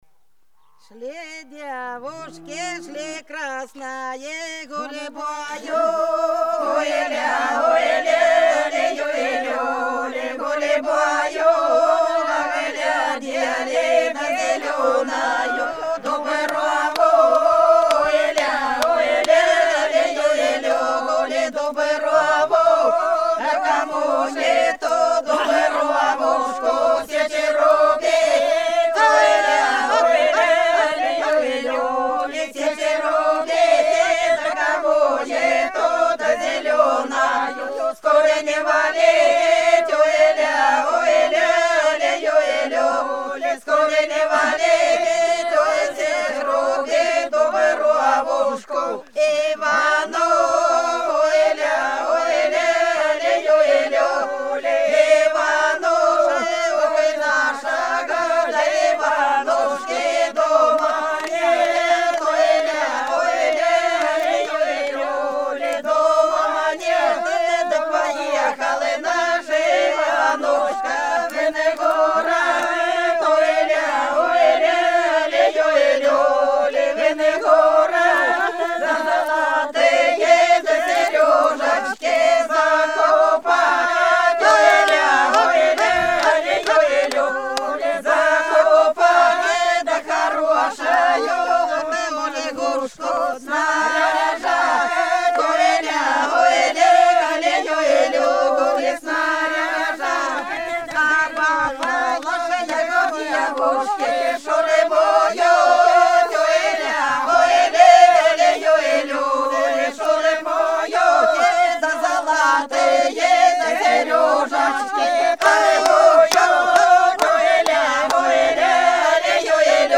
Белгородские поля (Поют народные исполнители села Прудки Красногвардейского района Белгородской области) Шли девушки, шли красные гульбою - свадебная